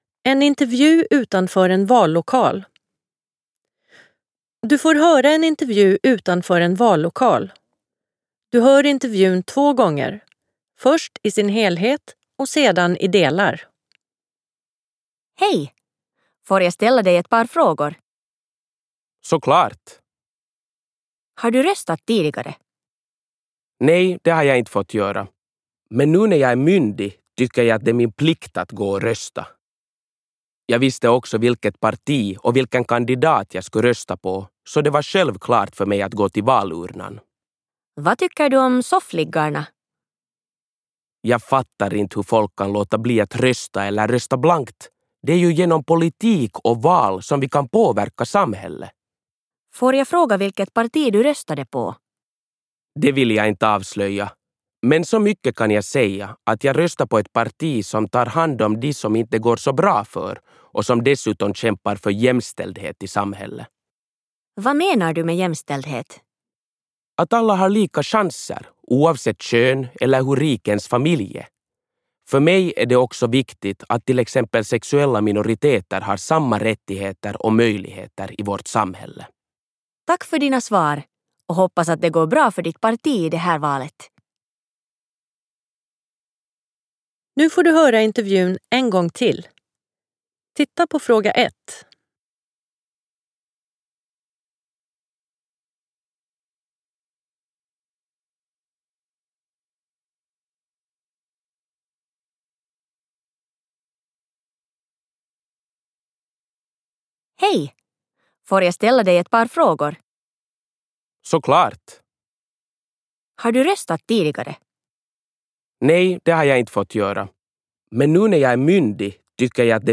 22_Samhalle_Intervju_vallokal_1.mp3